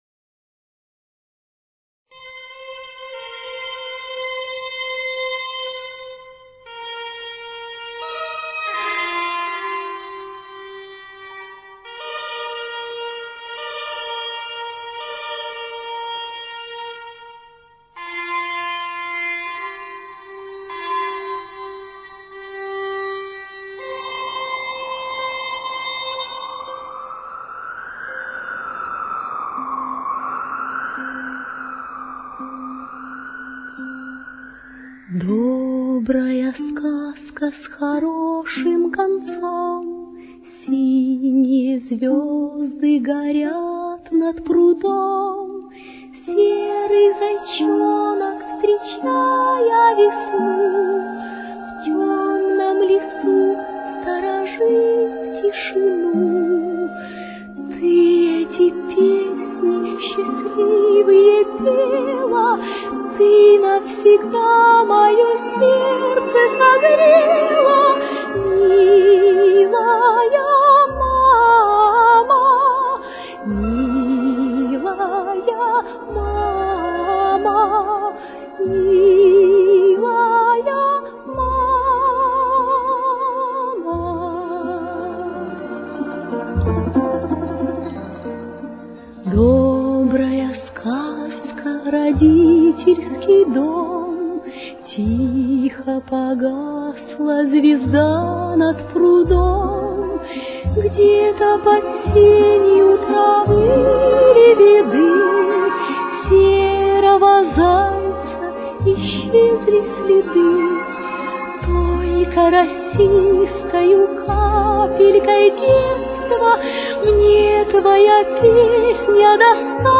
Фа мажор. Темп: 94.